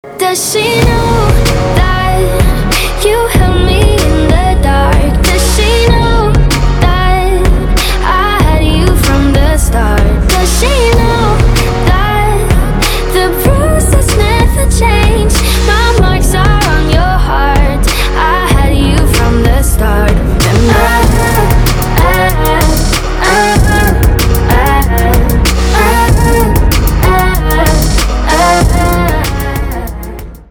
• Качество: 320, Stereo
поп
красивый женский голос